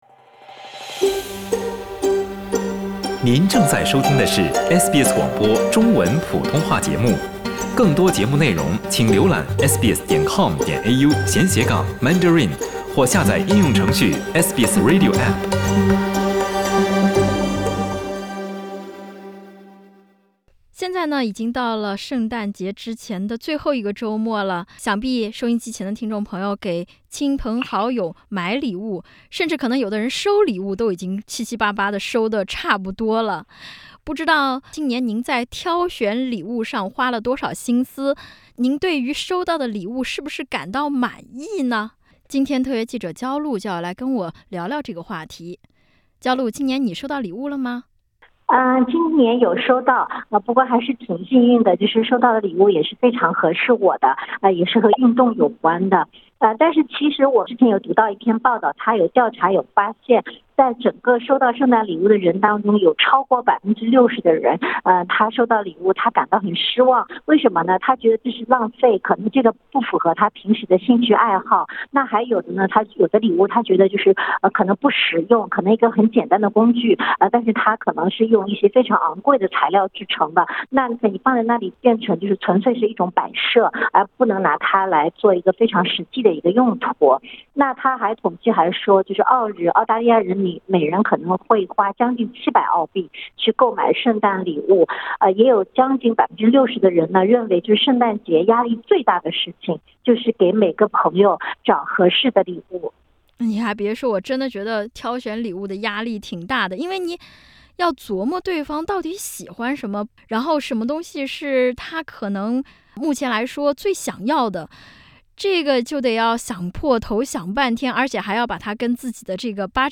Source: Caiaimage SBS 普通话电台 View Podcast Series Follow and Subscribe Apple Podcasts YouTube Spotify Download (21.63MB) Download the SBS Audio app Available on iOS and Android 都说“礼轻情意重”，在澳洲如果送出“二手礼物”，对方会不开心吗？